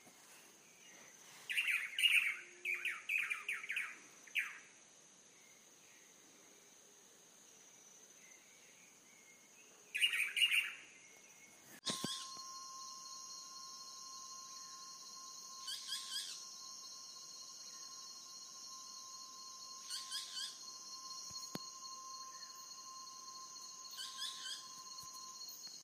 Pitanguá (Megarynchus pitangua)
Nombre en inglés: Boat-billed Flycatcher
Fase de la vida: Adulto
Localidad o área protegida: Reserva Privada y Ecolodge Surucuá
Condición: Silvestre
Certeza: Vocalización Grabada